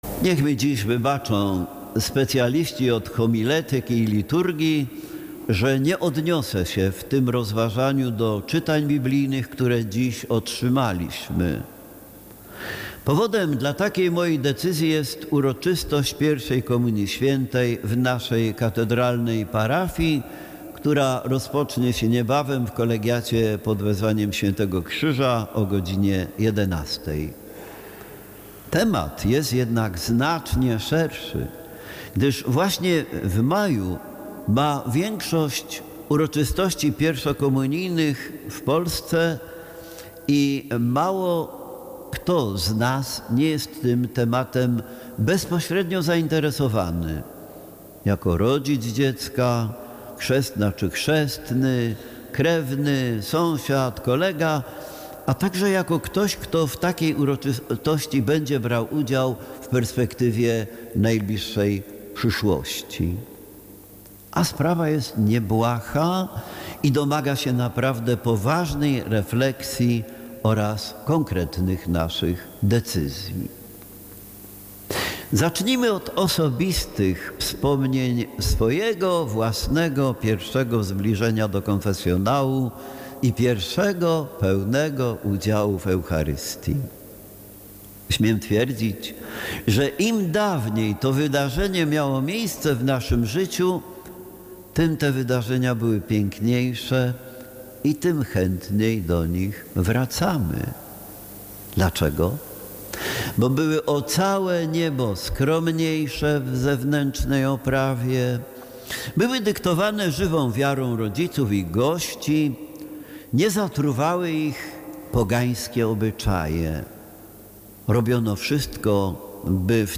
Posłuchaj kazania z mszy św. Z 14.05.2023. z godziny 10.00 transmitowanej w Radiu Rodzina:
kazanie-14.05.mp3